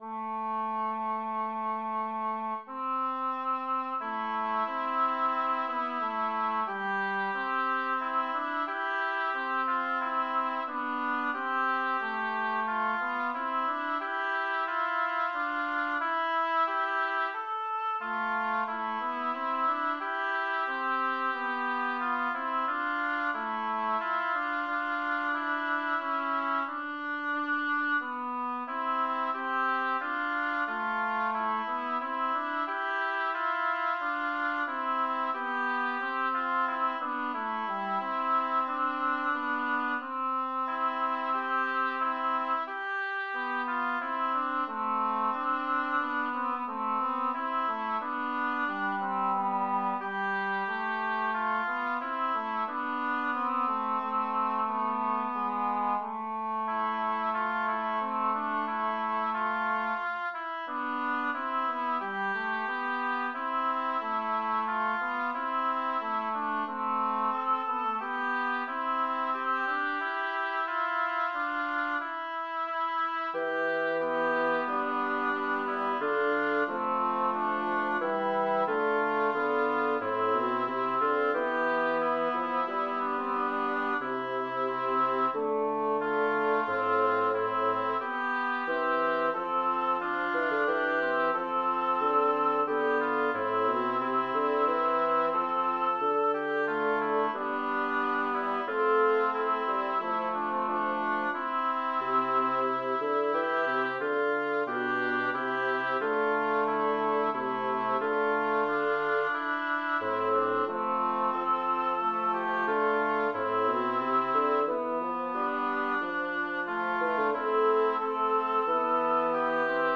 Language: Italian Instruments: A cappella